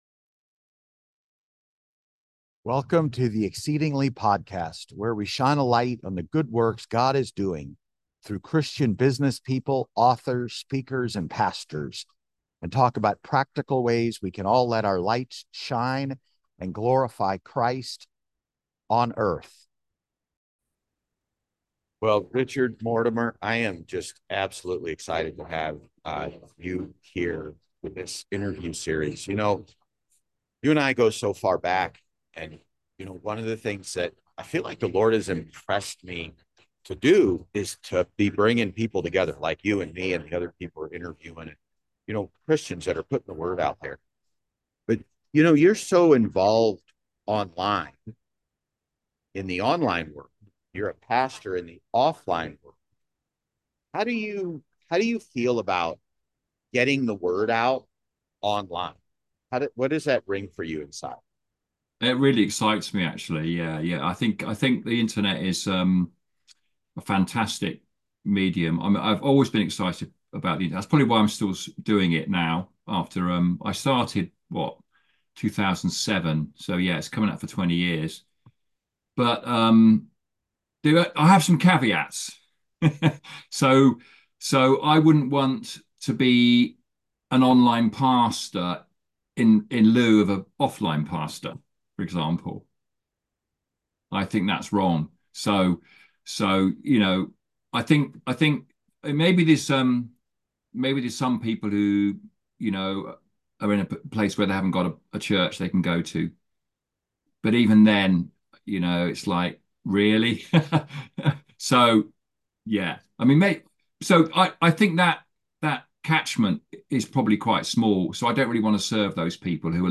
I am just absolutely excited to have you here with this interview series.